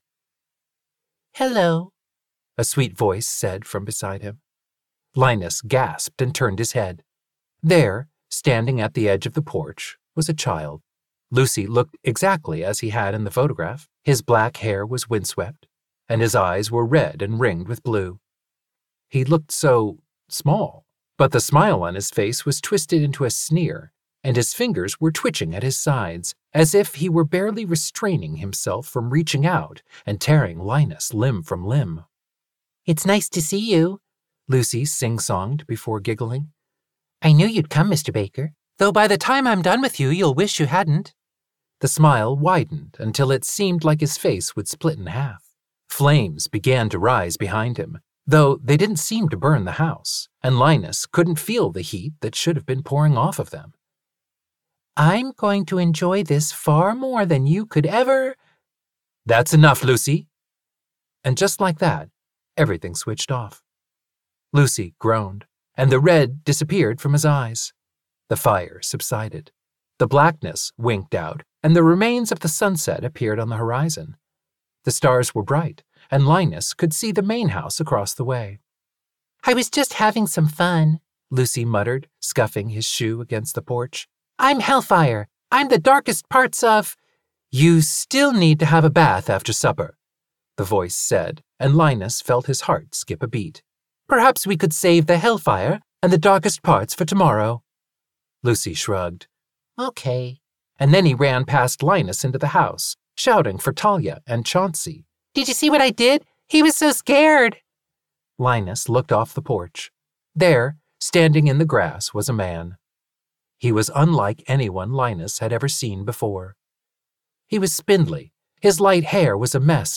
Audio Excerpt: Linus Meets Lucy and Arthur in The House in the Cerulean Sea!